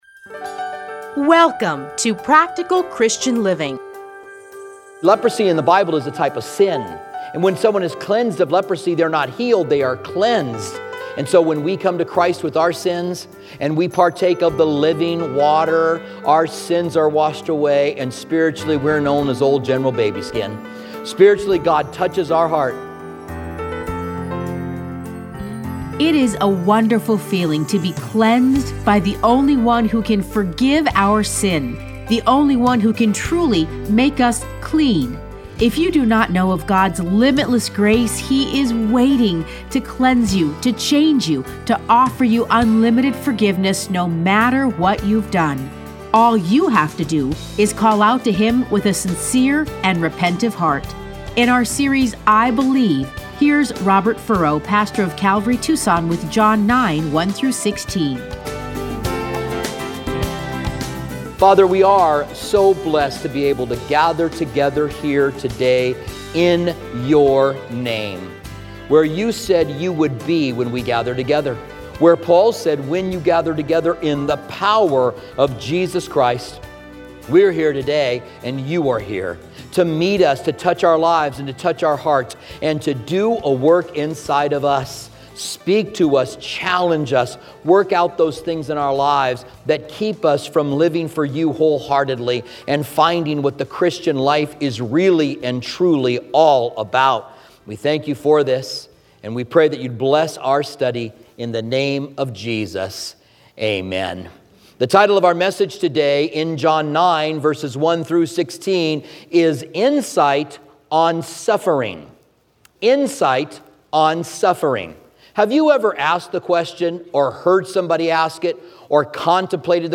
Listen to a teaching from John 9:1-16 .